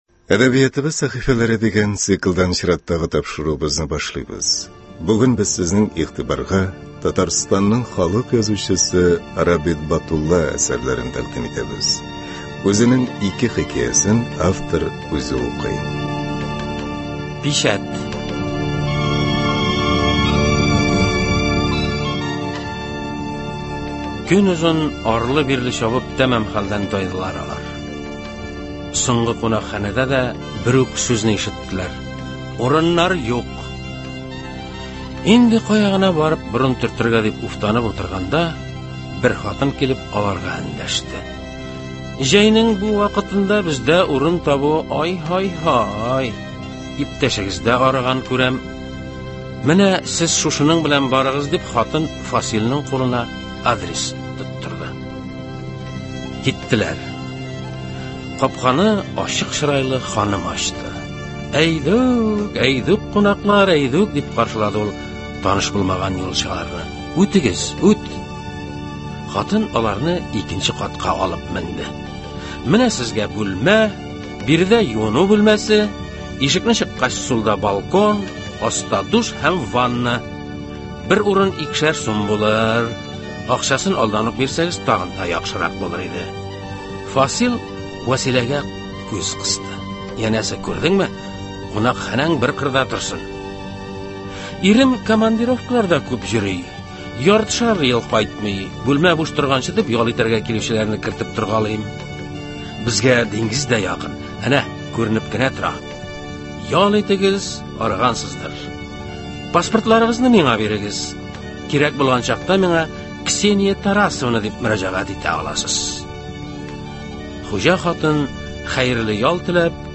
Татарстанның халык язучысы Рабит Батулла әсәрләрен тыңларга чакырабыз. Әдипнең үзе укуында 2 хикәясен һәм 2 әкиятен тыңларга рәхим итегез.